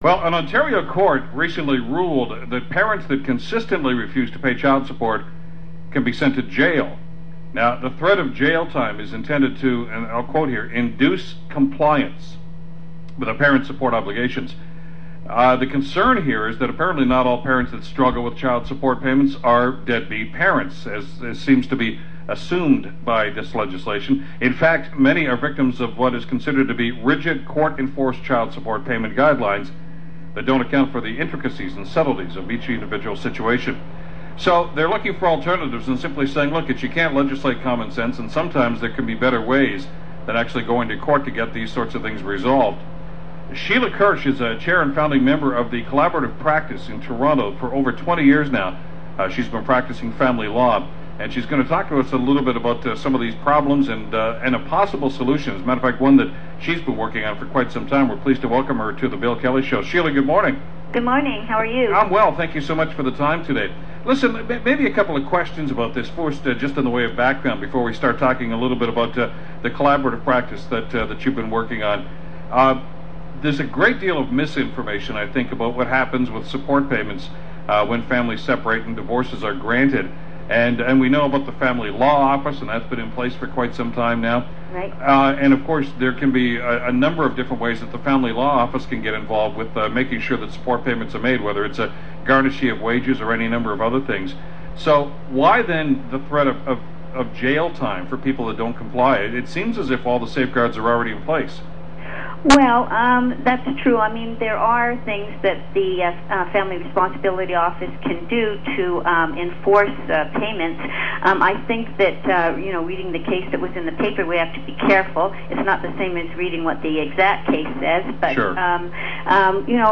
Radio interview regarding Collaborative Practice on AM900 CHML on December 10, 2008.